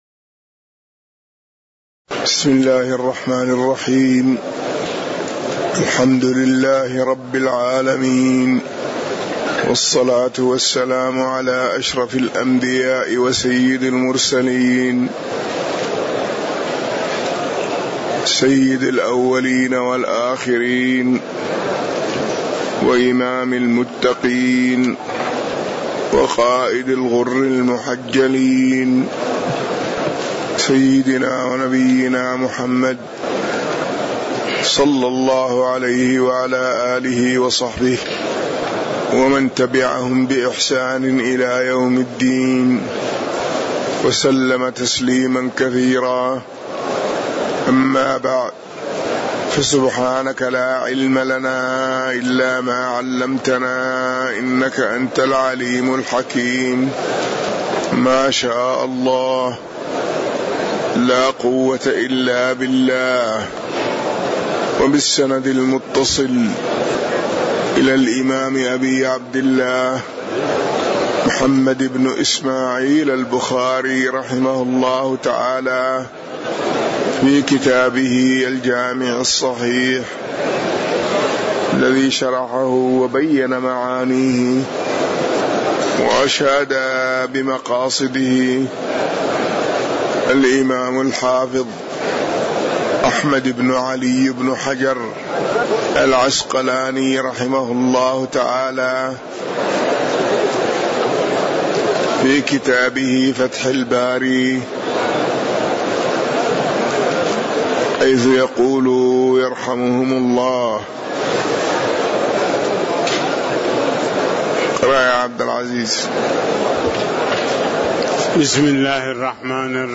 تاريخ النشر ٣ رمضان ١٤٤٠ هـ المكان: المسجد النبوي الشيخ